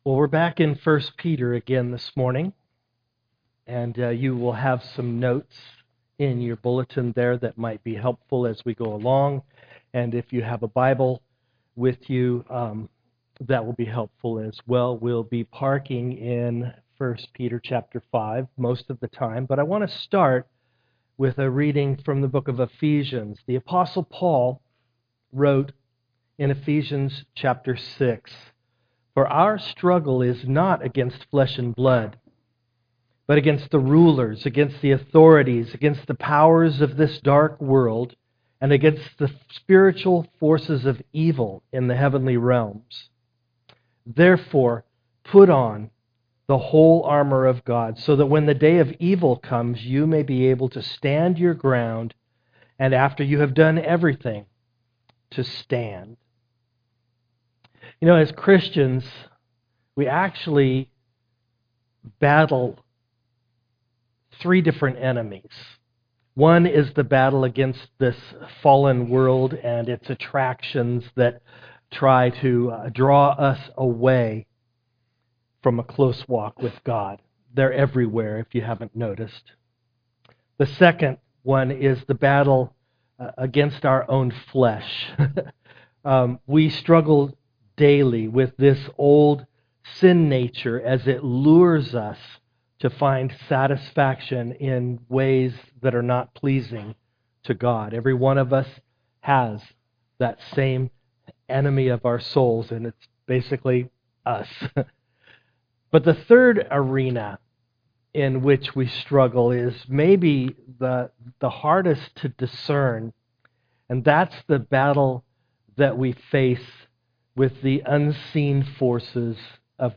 1 Peter 5:5-11 Service Type: am worship We face an enemy who seeks to destroy us.